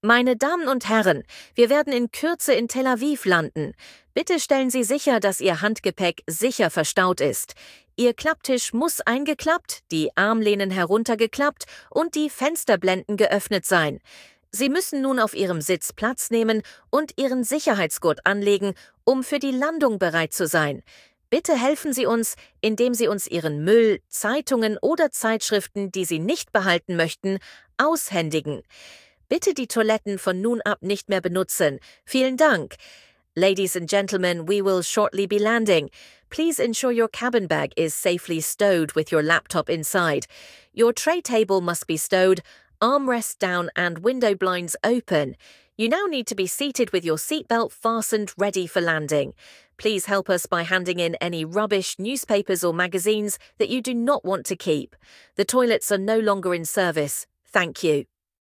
DescentSeatbelts.ogg